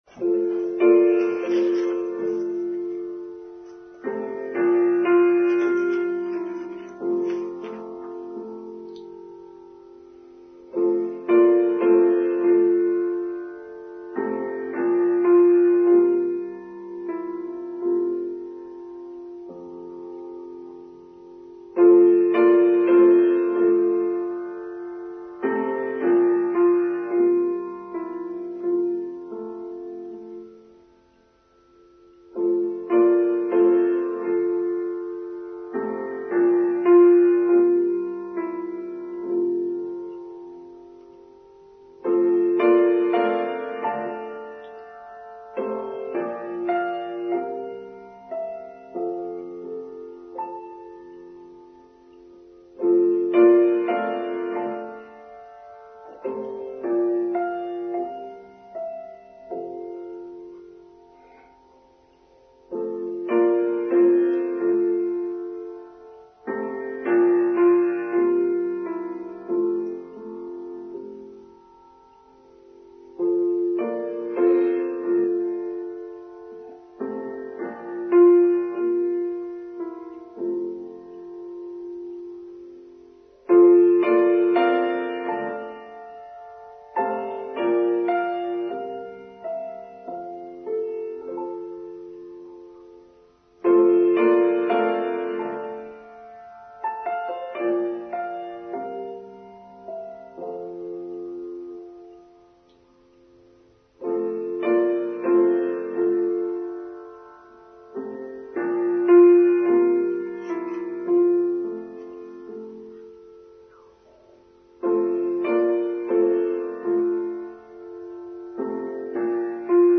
Being Together in Community: Online Service for Sunday 16th April 2023